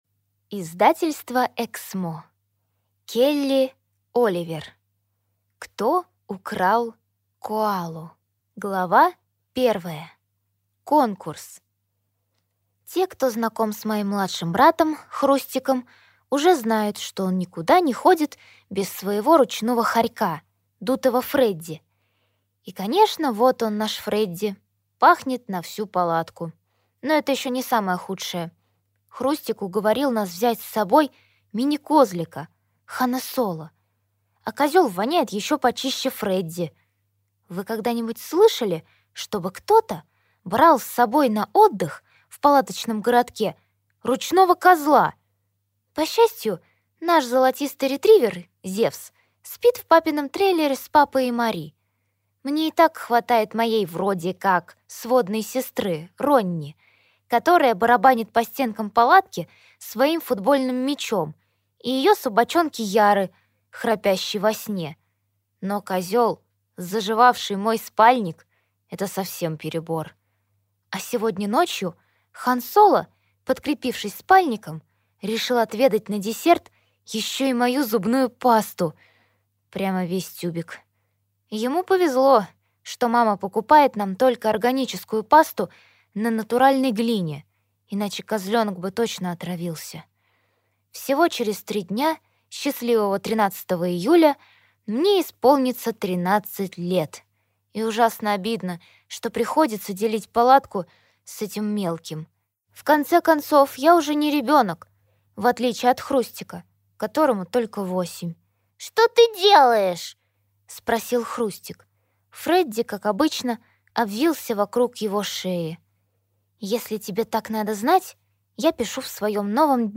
Аудиокнига Кто украл коалу?